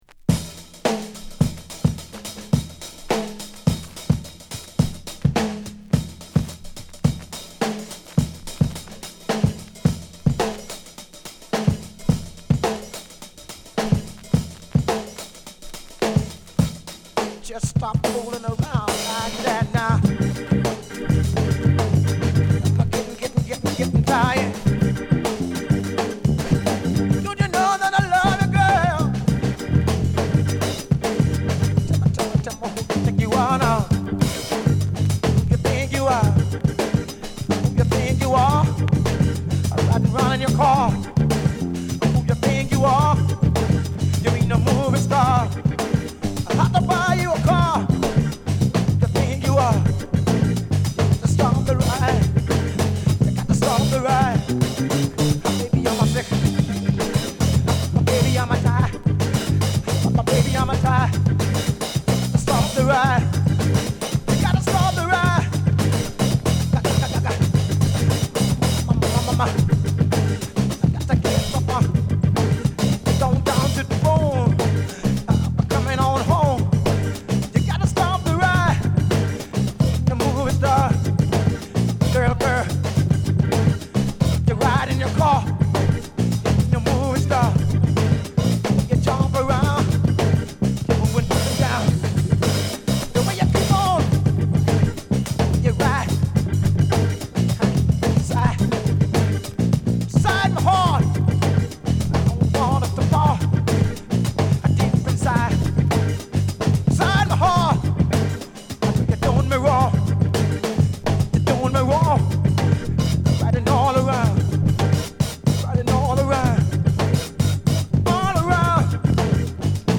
バハマ産レアグルーヴ！！